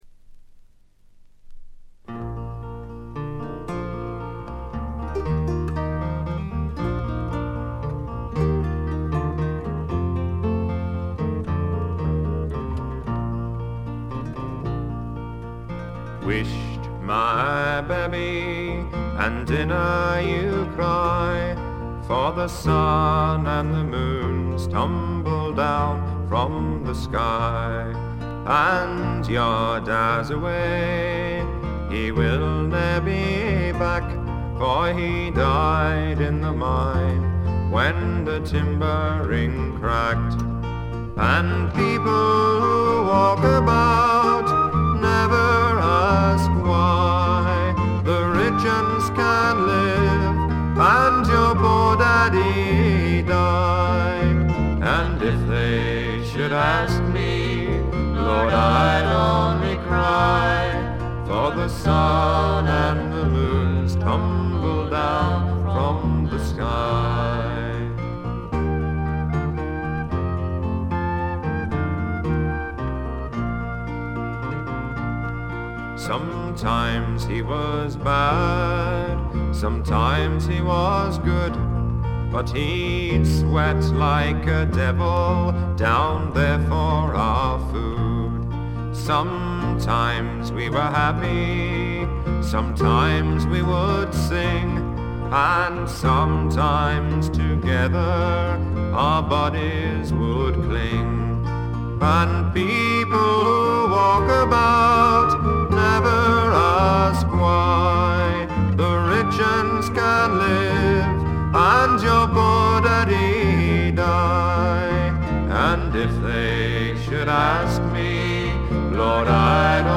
軽微なバックグラウンドノイズのみ。
試聴曲は現品からの取り込み音源です。